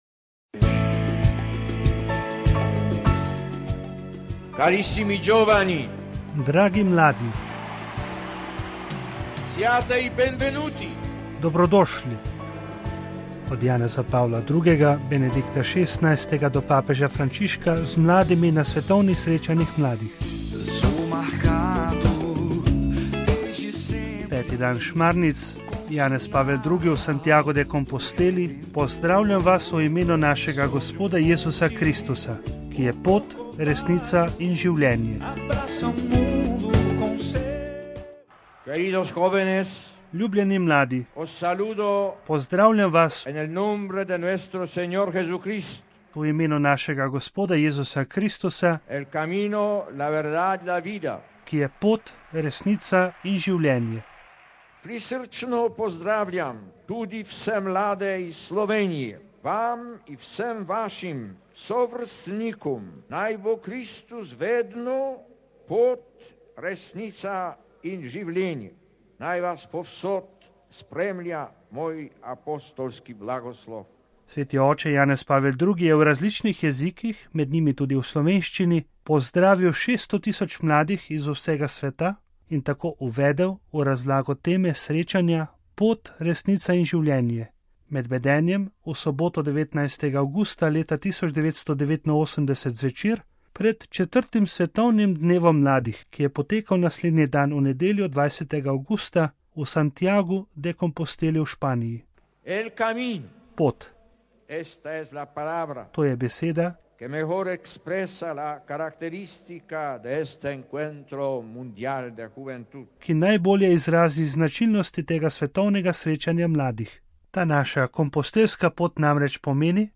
Sveti oče Janez Pavel II. je v različnih jezikih, med njimi tudi v slovenščini, pozdravil šesto tisoč mladih iz vsega sveta in tako uvedel v razlago teme srečanja: Pot, Resnica in Življenje, med bedenjem v soboto, 19. avgusta 1989 zvečer, pred 4. svetovnim dnevom mladih, ki je potekal naslednji dan, v nedeljo 20. avgusta 1989 v Santiagu de Composteli v Španiji.